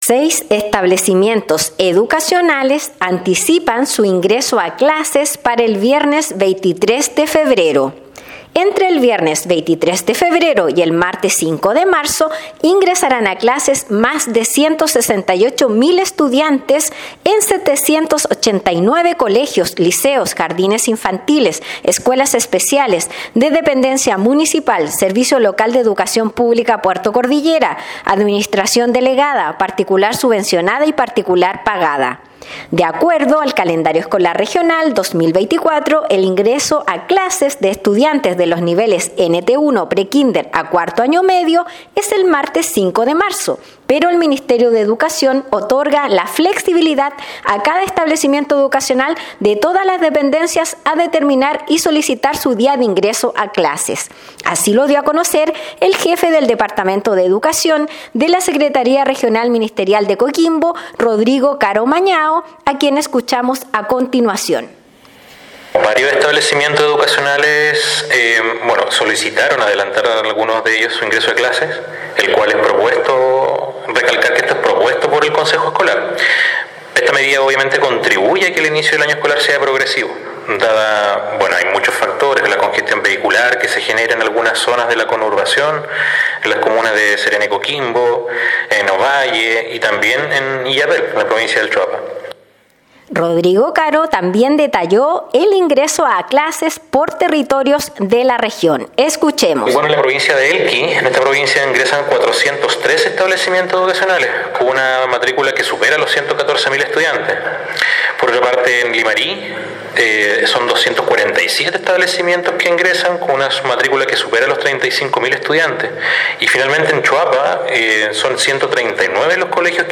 Despacho-Radial-6-Establecimientos-Educacionales-anticipan-su-ingreso-a-clases-para-el-viernes-23-de-febrero_.mp3